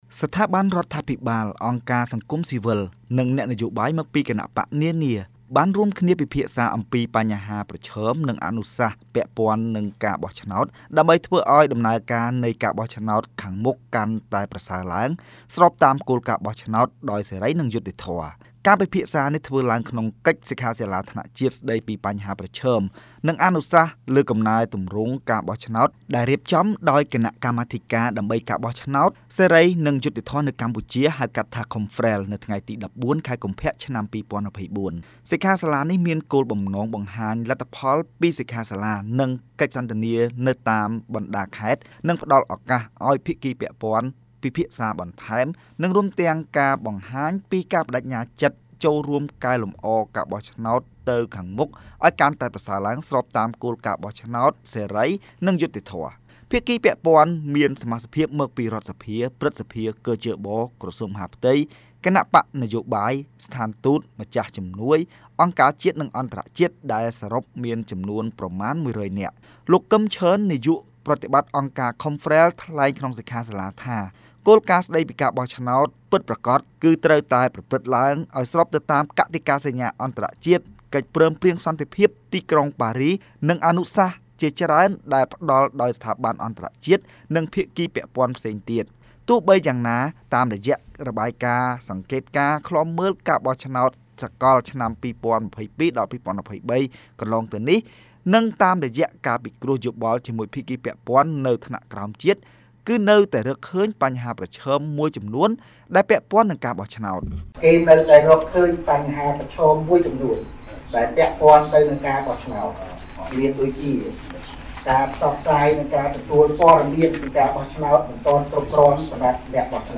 ការពិភាក្សាគ្នានេះ ធ្វើឡើងក្នុងសិក្ខាសាលាថ្នាក់ជាតិ ស្ដីពីបញ្ហាប្រឈម និងអនុសាសន៍លើកំណែទម្រង់ការបោះឆ្នោត ដែលរៀបចំដោយគណៈកម្មាធិការដើម្បីការបោះឆ្នោតដោយសេរី និងយុត្តិធម៌នៅកម្ពុជា (ខុមហ្វ្រែល) នៅថ្ងៃទី១៤ ខែកុម្ភៈ ឆ្នាំ២០២៤។ សិក្ខាសាលានេះមានគោលបំណងបង្ហាញលទ្ធផលពីសិក្ខាសាលា និងកិច្ចសន្ទនានៅតាមបណ្តាខេត្ត និងផ្តល់ឱកាសឱ្យភាគីពាក់ព័ន្ឋ ពិភាក្សាបន្ថែម និងរួមទាំងការបង្ហាញពីការប្ដេជ្ញាចិត្តចូលរួមកែលម្អការបោះឆ្នោតទៅខាងមុខឱ្យកាន់តែប្រសើរឡើង ស្របតាមគោលការណ៍បោះឆ្នោតដោយសេរី និងយុត្តិធម៌។